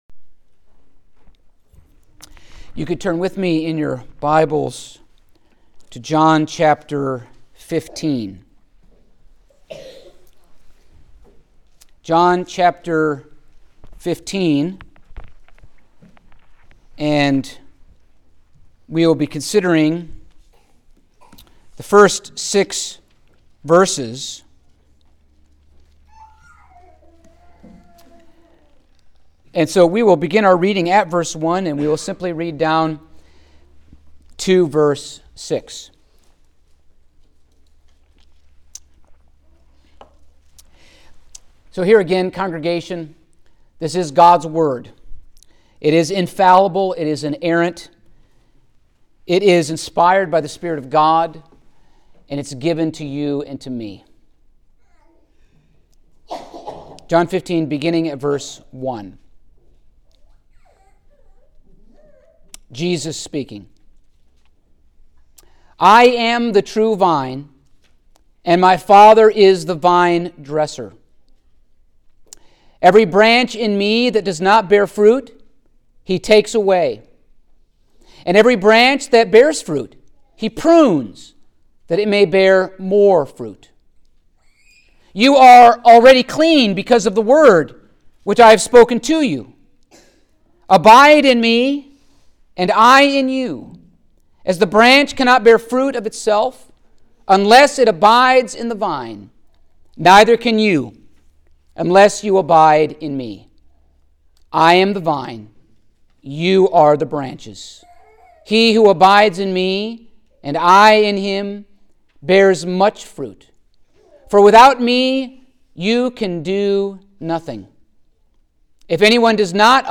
The Gospel of John Passage: John 15:1-6 Service Type: Sunday Morning Topics